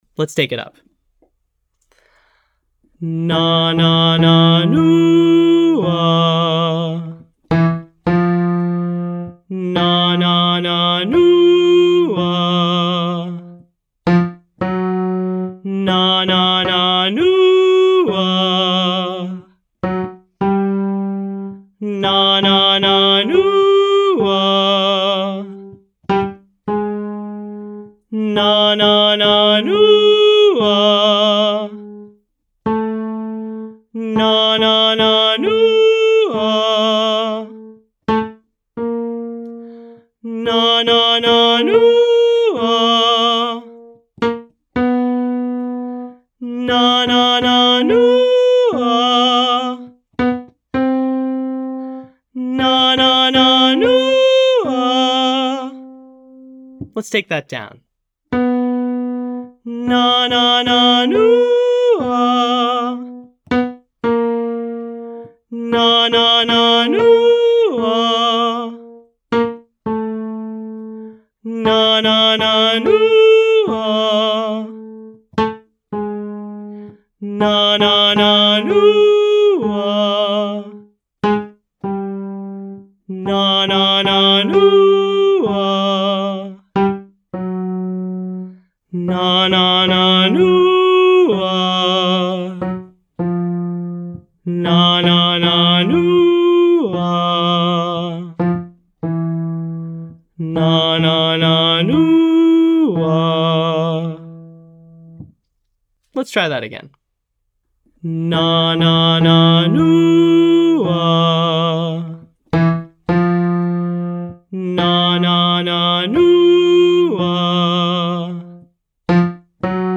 Exercise: Chest & head - NAH-oo-Ah 18531 E
Pop Daily Practice Lesson 5A